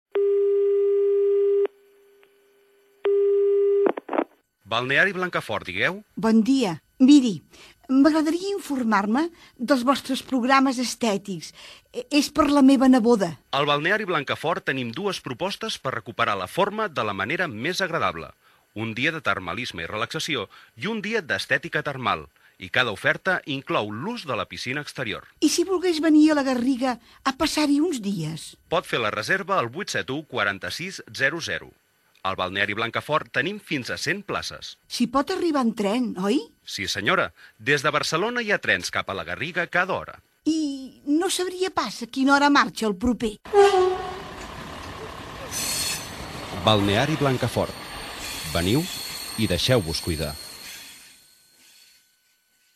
Gènere radiofònic Publicitat Anunciant Balneari Blancafort de La Garriga